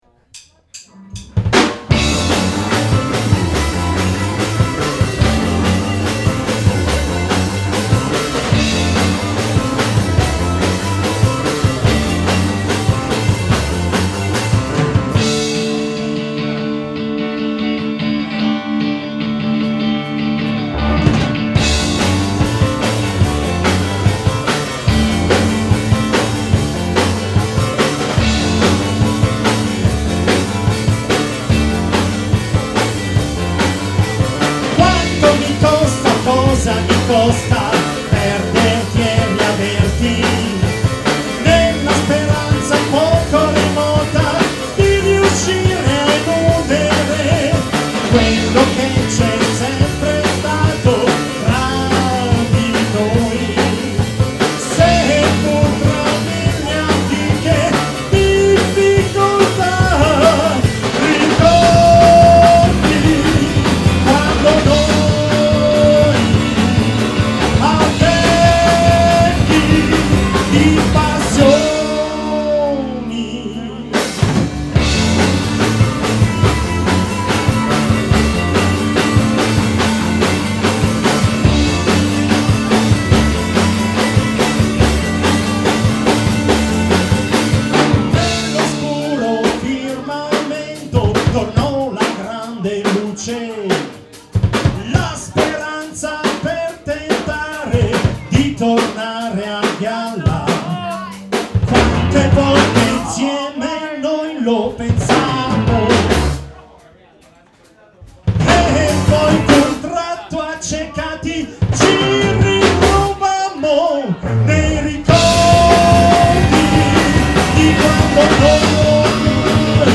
with ORTF stereo configuration,